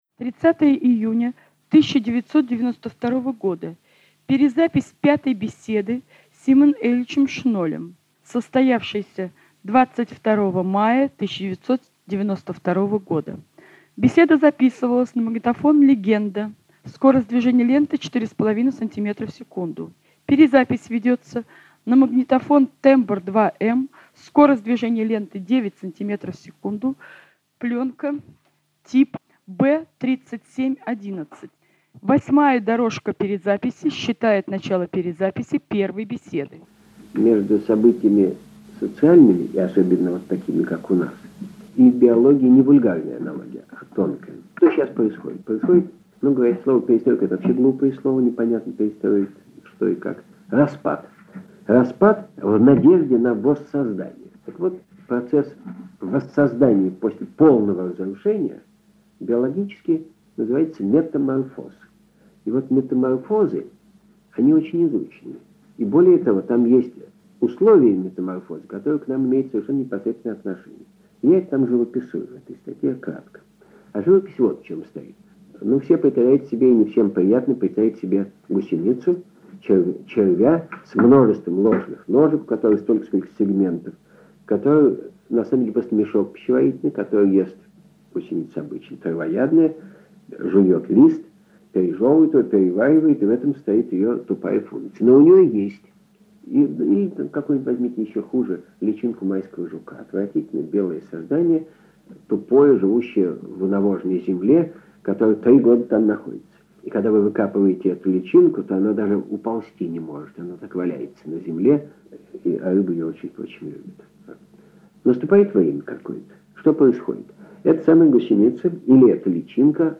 Шестая беседа с биофизиком Симоном Шнолем посвящена человеку исключительной значимости для нашего собрания — Ивану Георгиевичу Петровск
Устная история